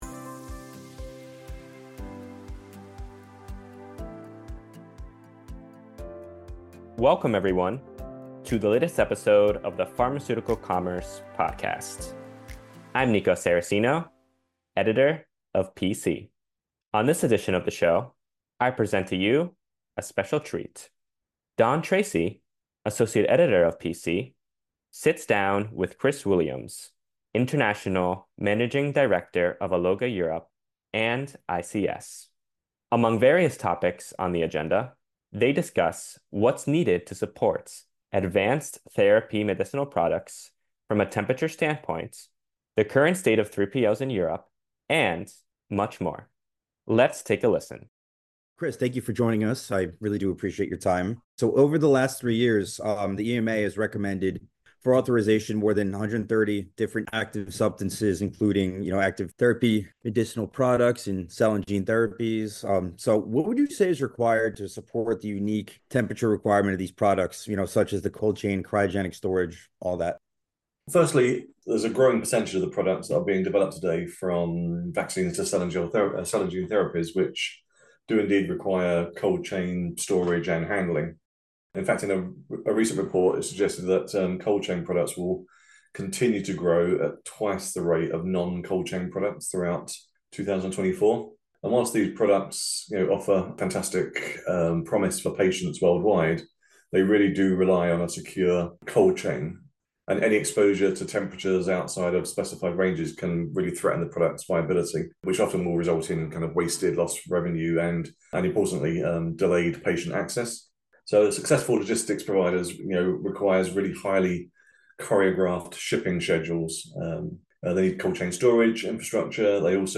In this exclusive PC Podcast interview